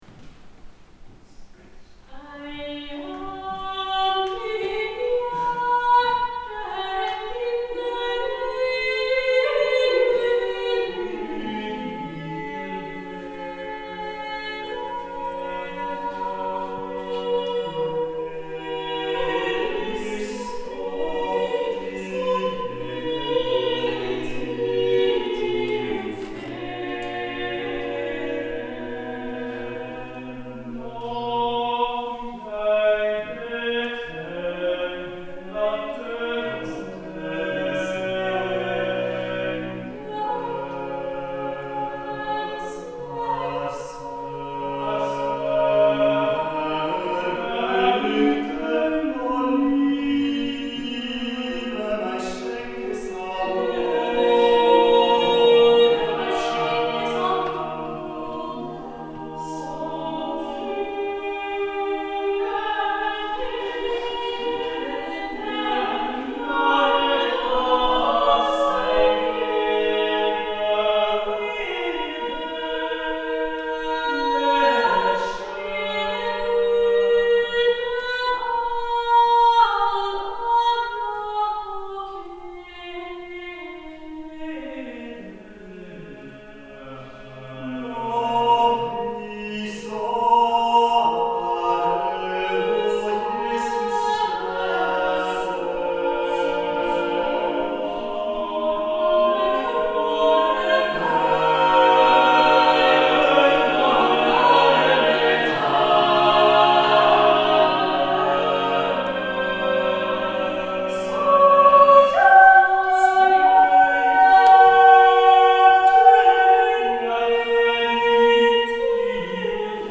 Eia mitt hjerte SSATB – Nye Noter
Eia_mitt_hjerte_-_Early_Voices_liveopptak_2024.mp3